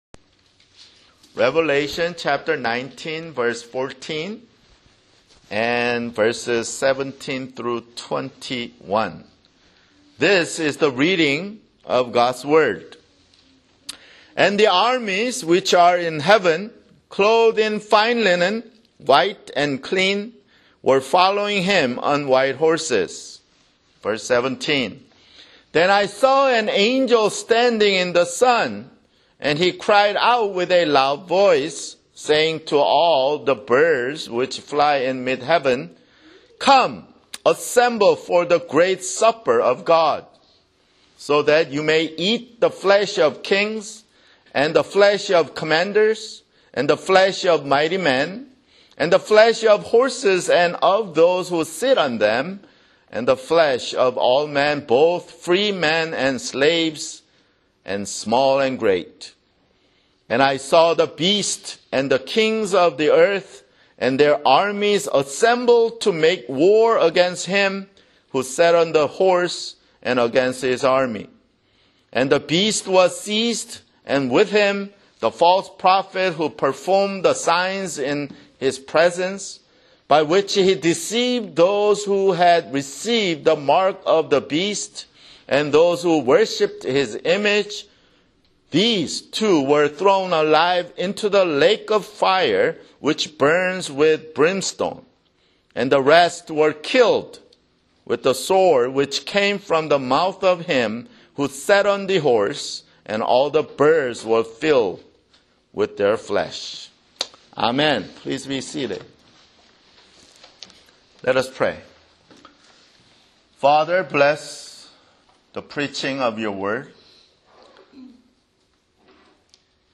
[Sermon] Revelation (75)